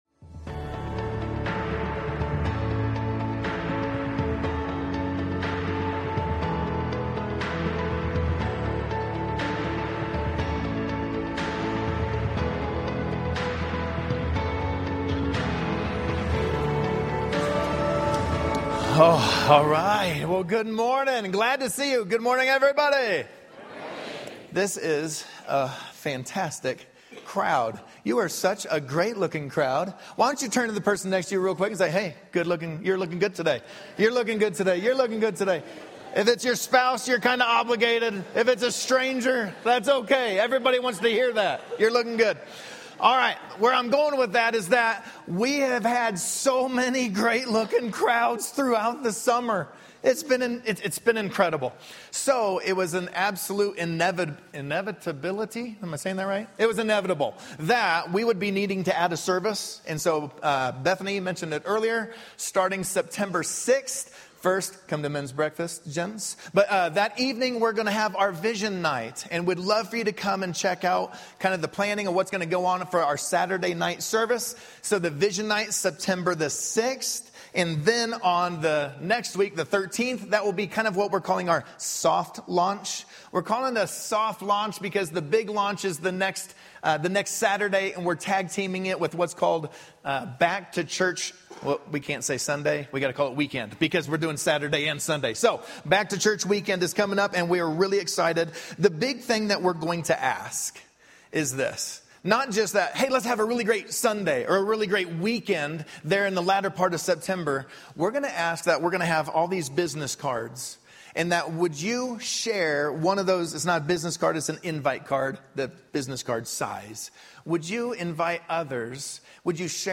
" We Like Giving " Sermon Notes Facebook Tweet Link Share Link Send Email